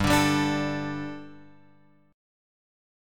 G Suspended 2nd